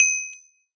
successful_hit.ogg